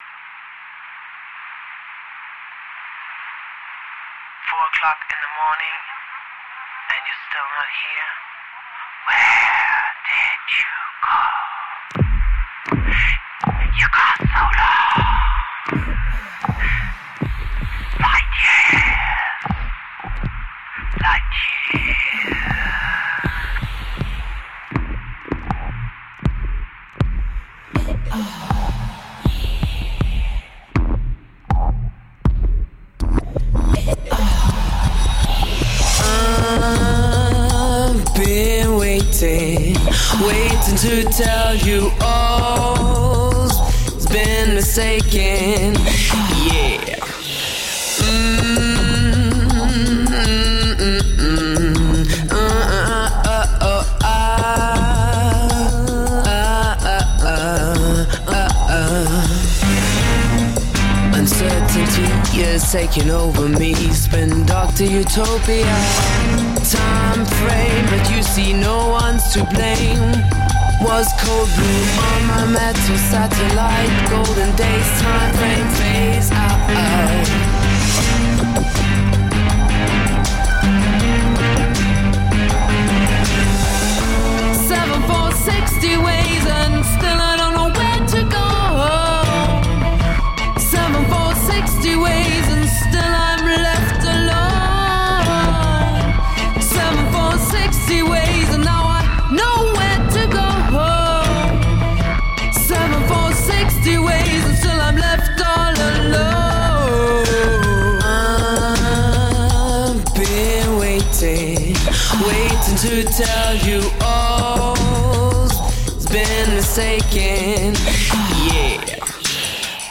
Vivid world-electronica grooves.